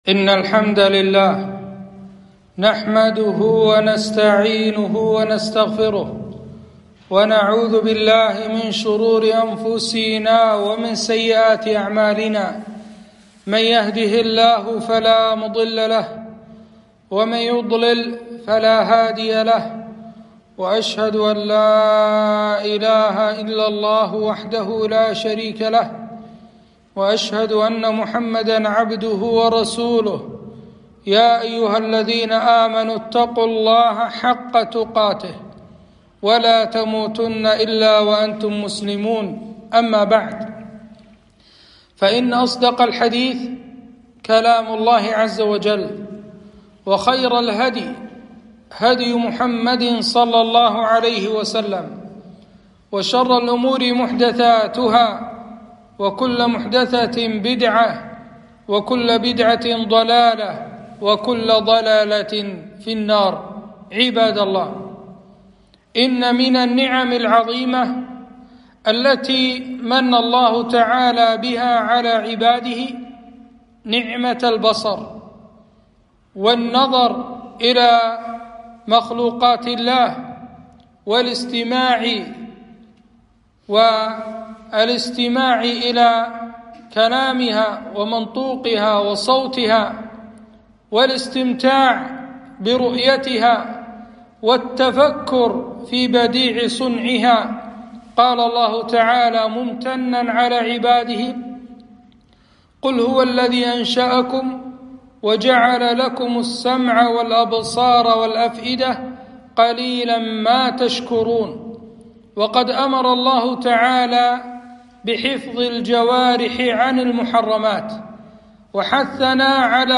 خطبة - غض البصر